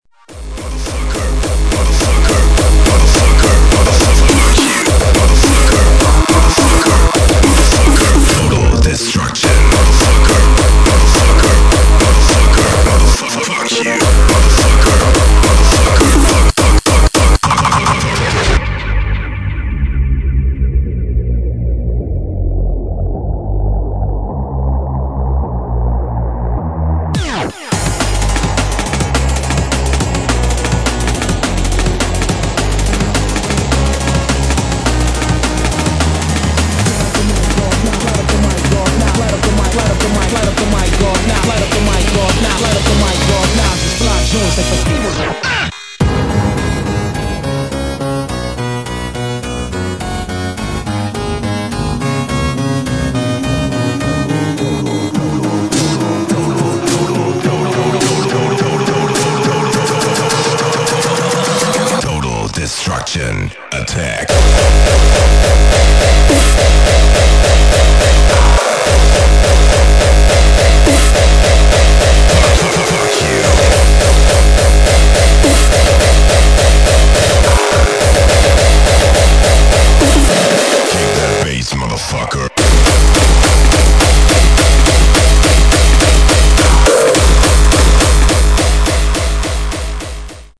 [ HARDCORE ]